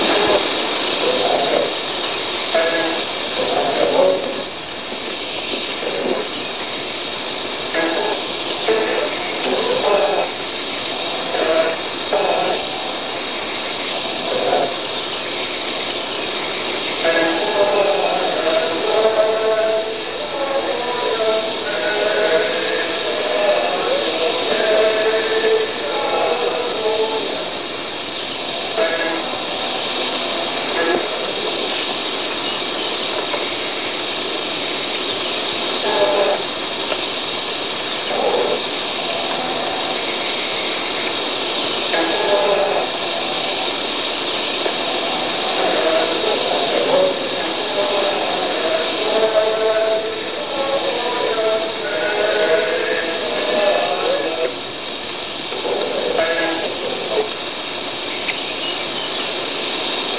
audio example: music piece "Mwavaaz" third phrase (tape) Kochtopf